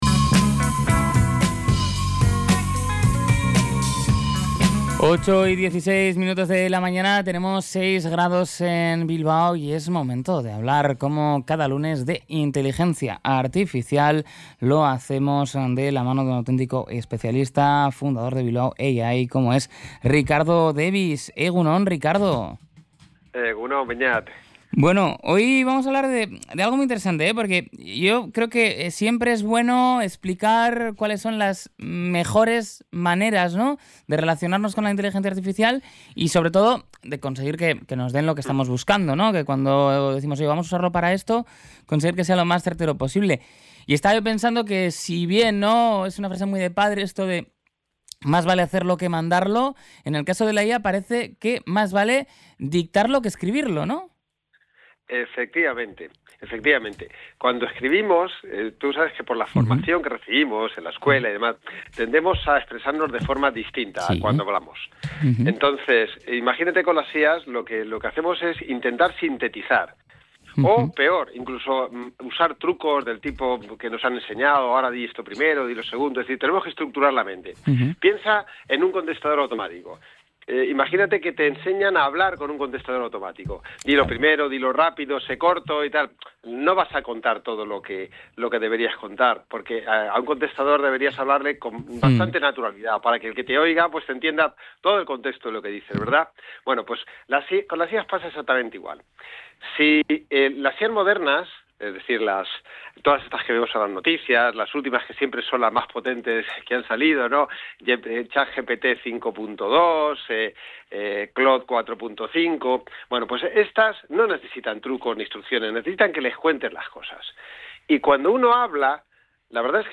Durante la charla, el invitado ha mencionado WhisperFlow y SuperWhisper como ejemplos de aplicaciones que se han integrado con éxito en los flujos de trabajo habituales.